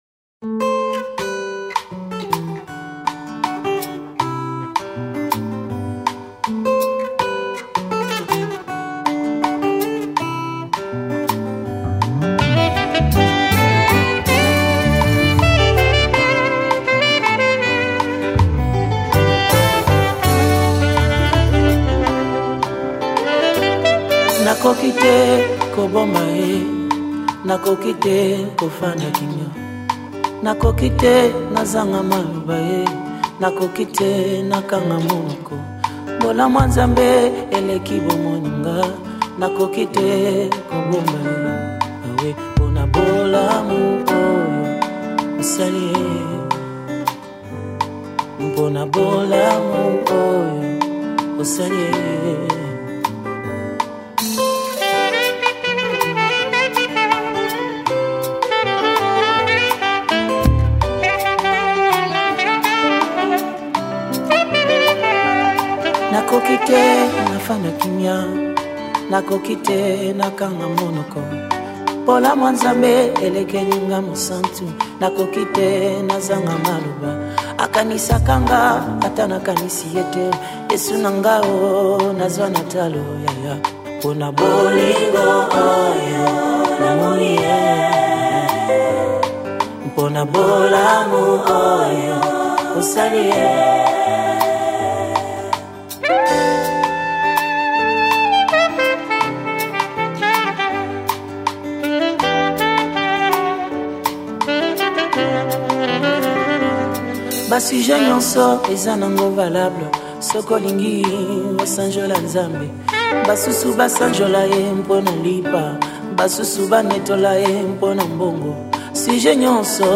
Gospel 2013